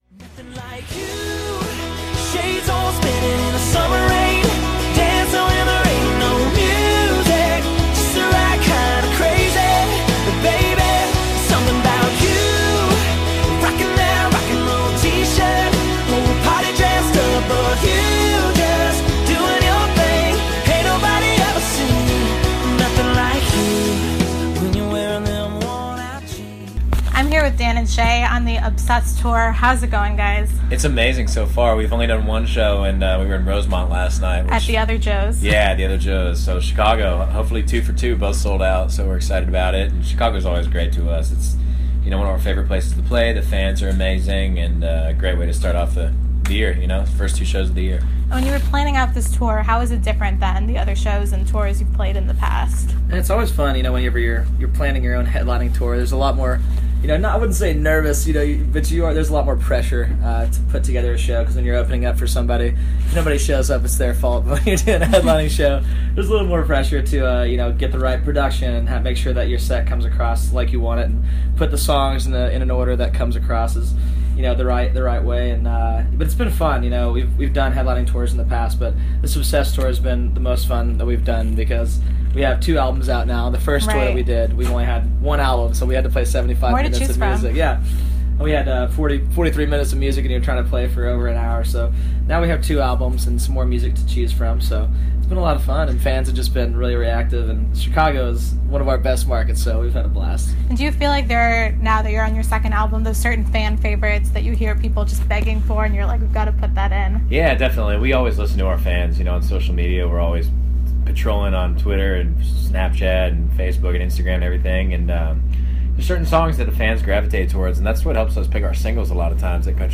Dan + Shay Interview